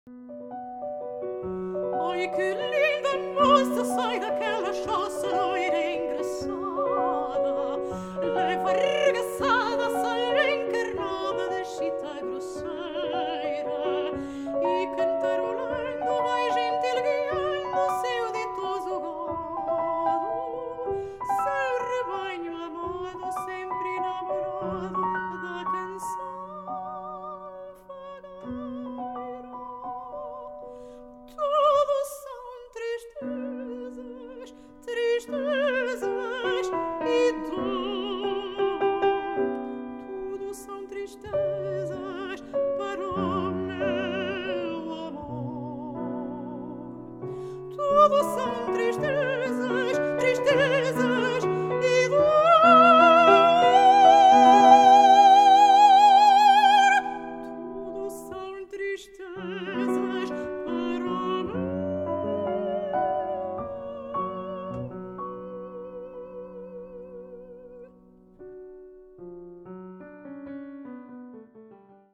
Portuguese Soprano
PORTUGUESE SONGS